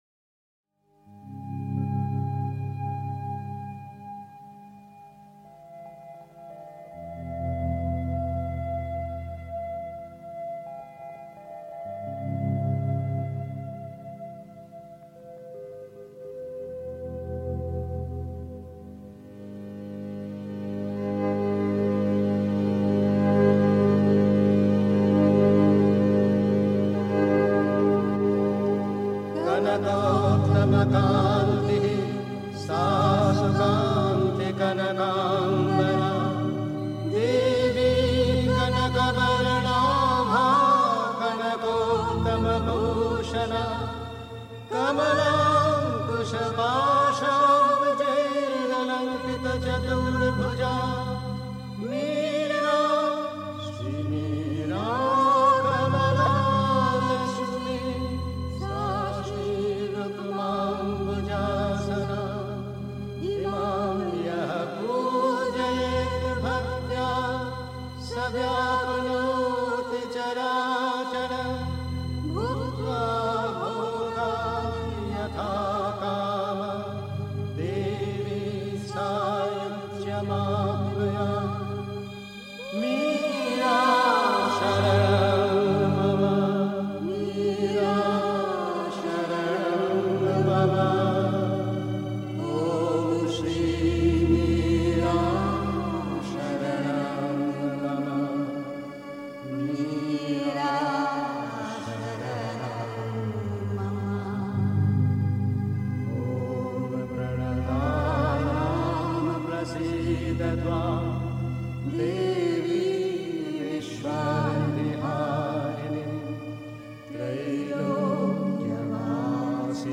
Pondicherry. 2. Das Göttliche ist absoluter Frieden (Die Mutter, Weisse Rosen, 15. April 1958) 3. Zwölf Minuten Stille.